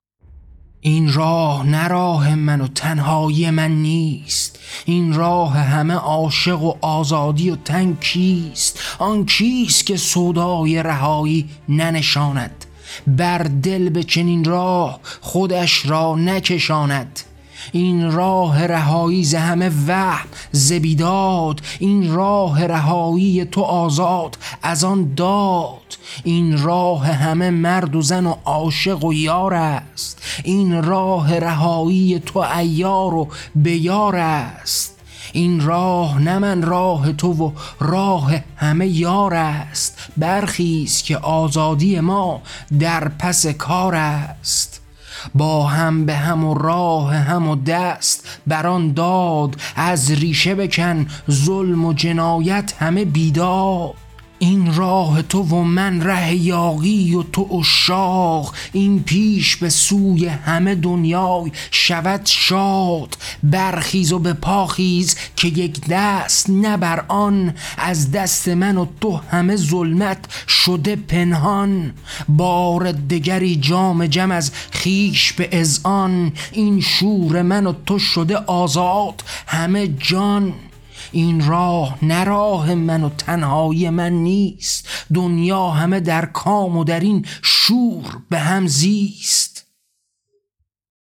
کتاب طغیان؛ شعرهای صوتی؛ با هم: مانیفست اتحاد و پیکار جمعی برای آزادی